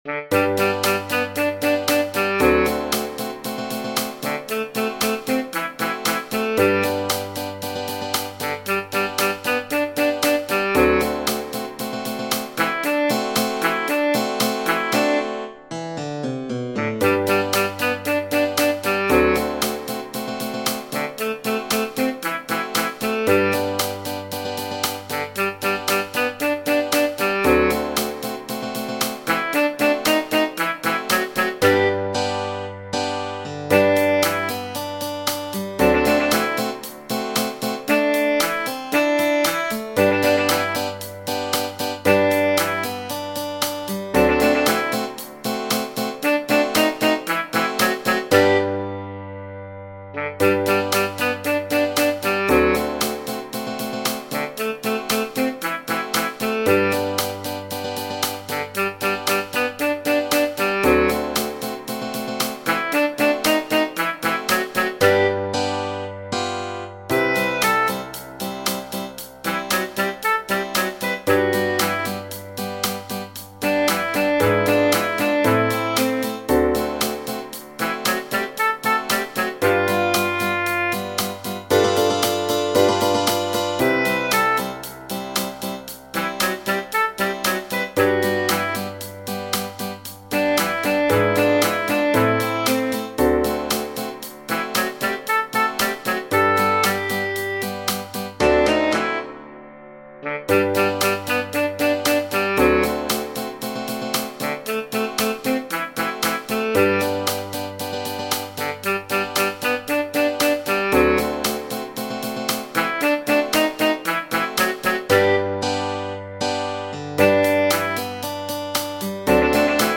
Genere: Moderne
a ritmo di cha cha cha